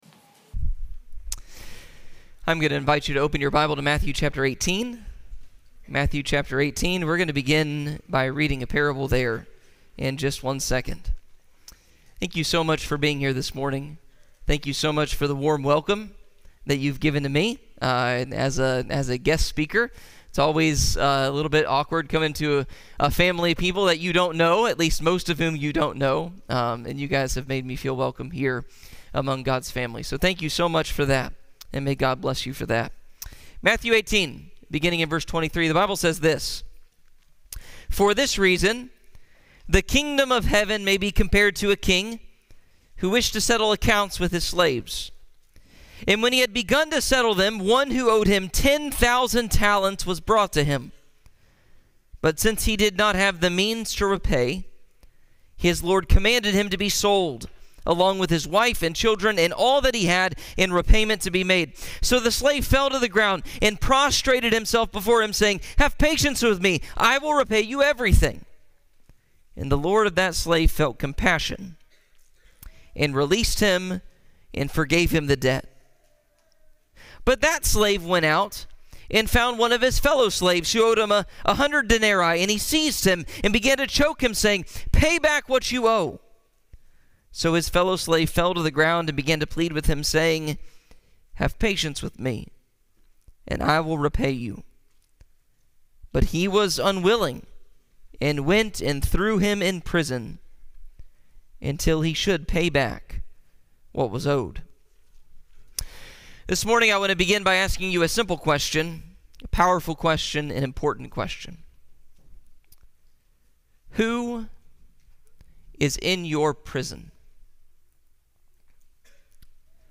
9-21-25_Sermon.MP3